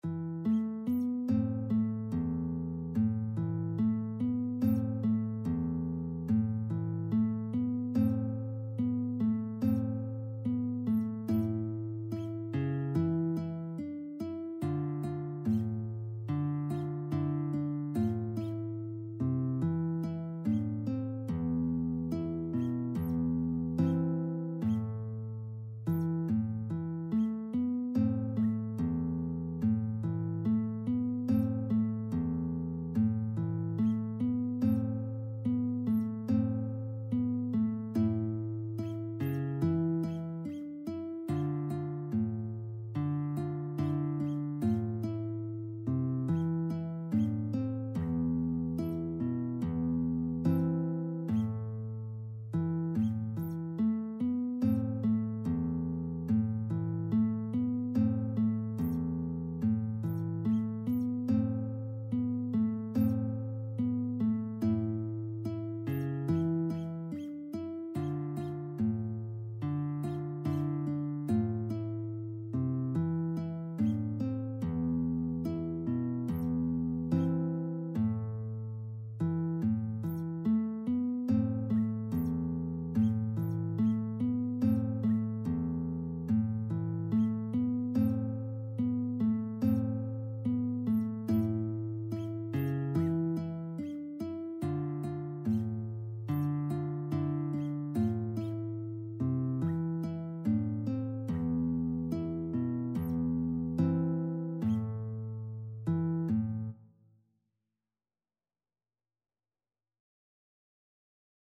Bella Ciao Free Sheet music for Guitar
Guitar version
bella_ciao_GT.mp3